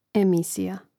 emìsija emisija